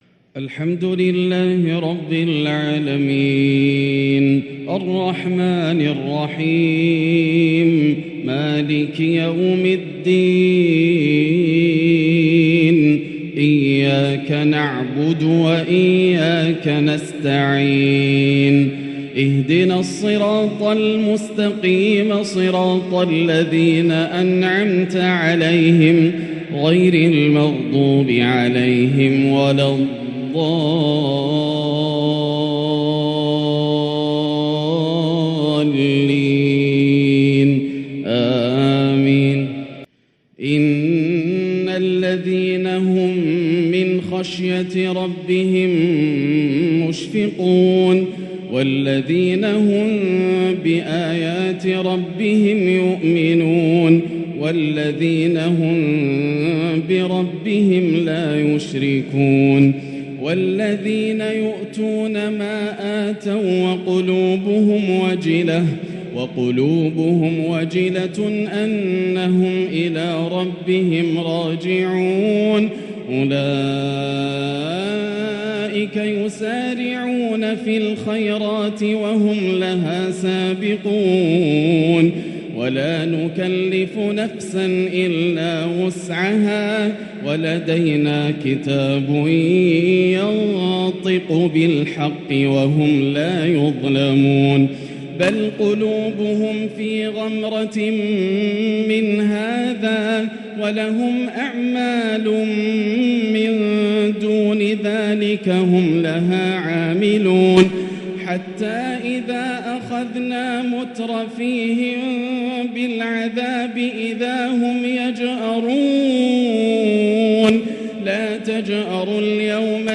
“وجاءت سكرة الموت بالحق" بالأداء الشهير يحبر د. ياسر الدوسري تلاوة عظيمة من روائع العشائيات > مقتطفات من روائع التلاوات > مزامير الفرقان > المزيد - تلاوات الحرمين